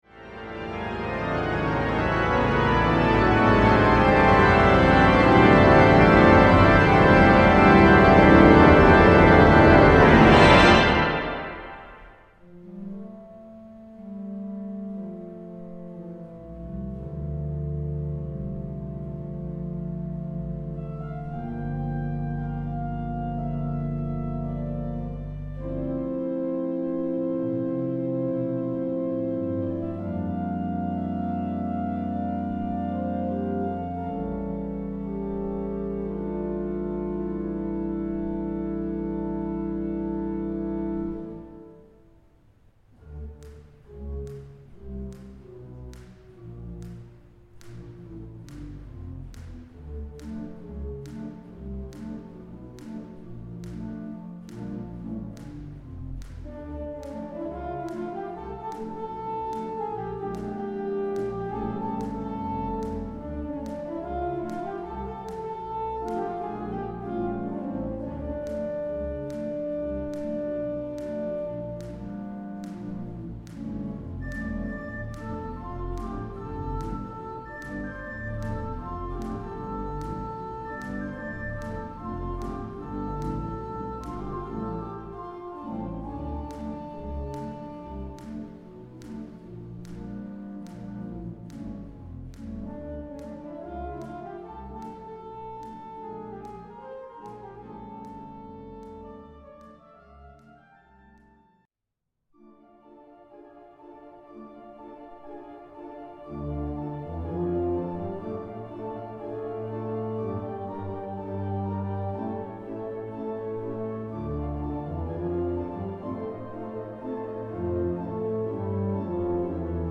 Konzert CD
WALCKER-Orgel von 1928.
Improvisation in d-moll
- molto destructivo
- swingendo sempre schnippendo